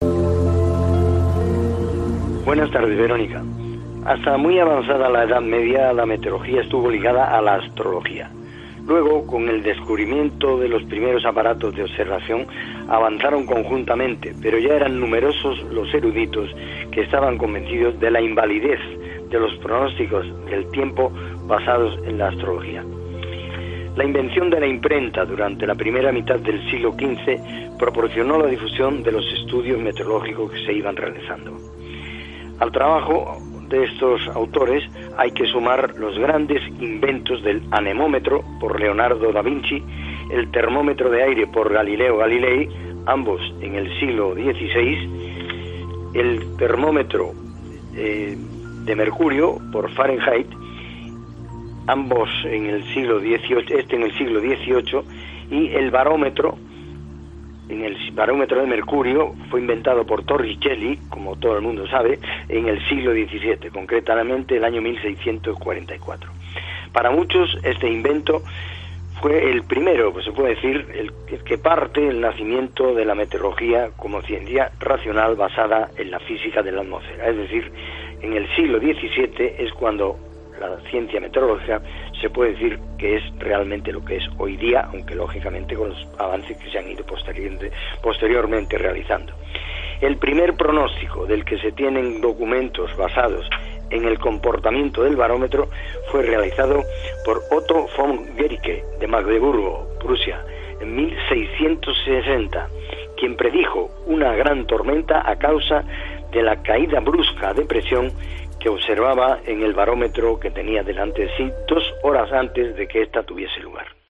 El interés por la meteorología y los intentos de predecirla y comprenderla vienen de muy lejos y nos lo cuenta el meteorólogo, José Antonio Maldonado.